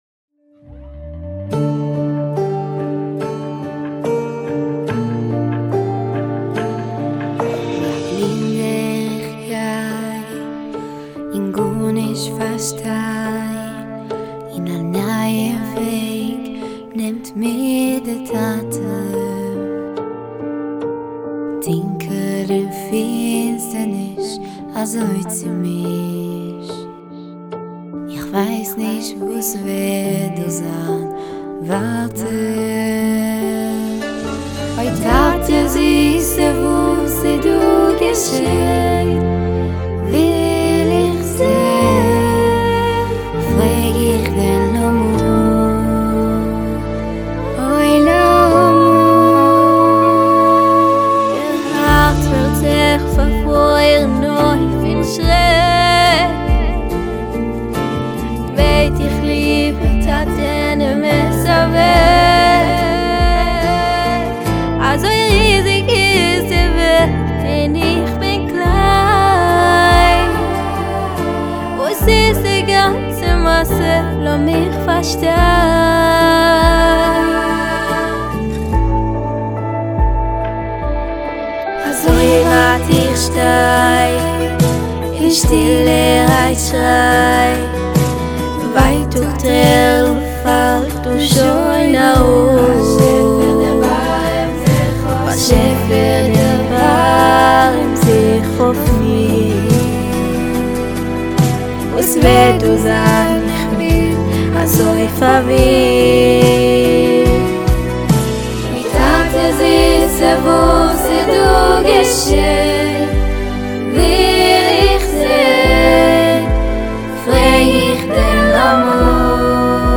הראשונים היו פצצה, נשמעו של אול-פלייבק, עכשו, אחרי שהפכתי ל'לקוחה קבועה' שלהם, ירד קצת האיכות- אבל יוצא עדיין מטורף.